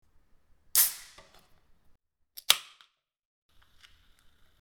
Звук открывающейся банки Кока-Колы